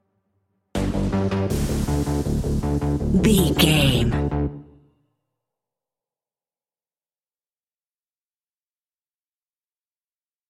Aeolian/Minor
D
ominous
dark
haunting
eerie
synthesiser
strings
drums
percussion
horror music